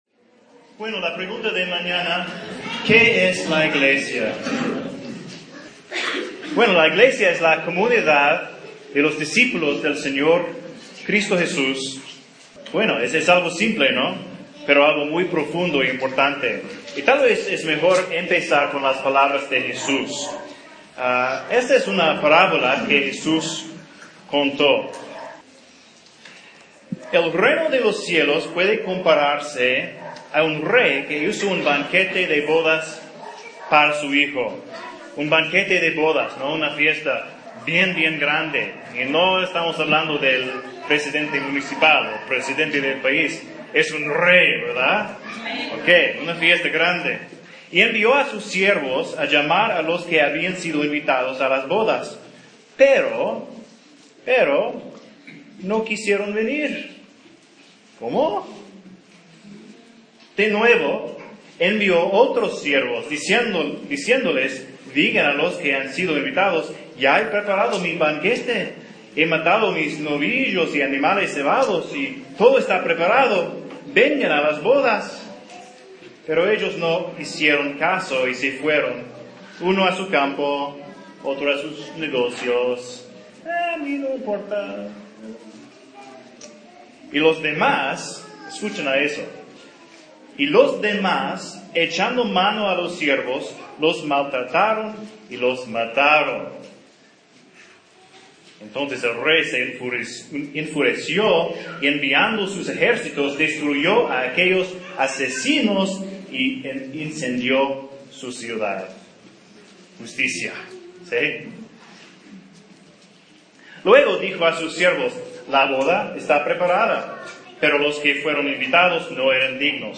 La Iglesia (sermón)
Un sermón sobre la pregunta – ¿Qué es la Iglesia?